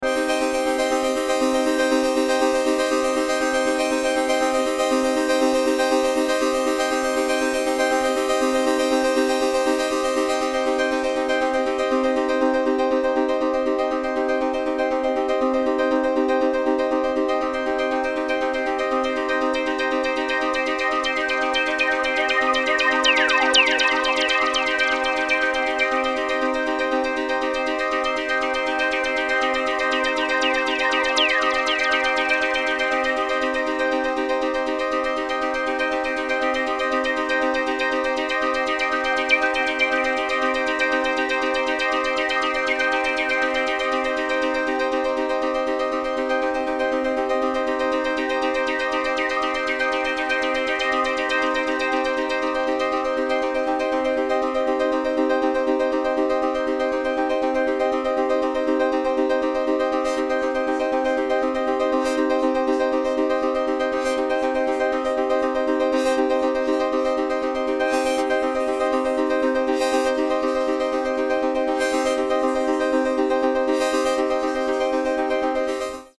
Genres: Background Music
Tempo: 120 bpm